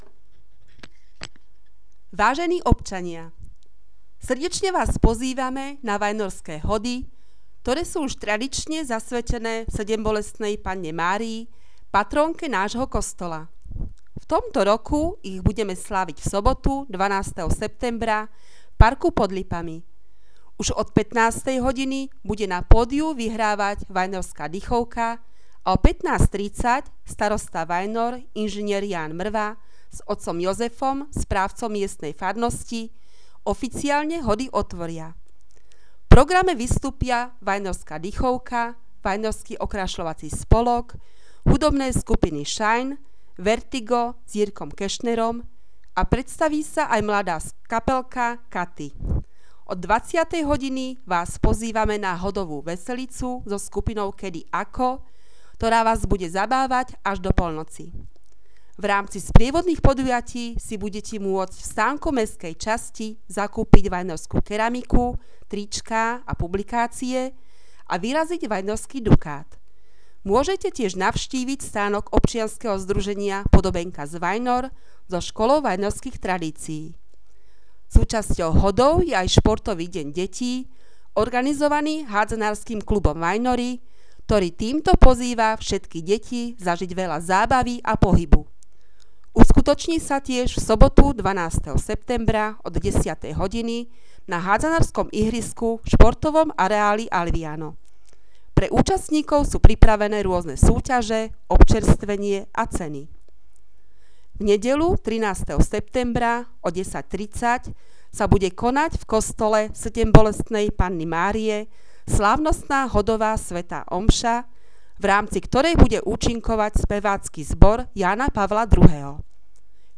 Hlásenie miestneho rozhlasu 10.,11.9.2015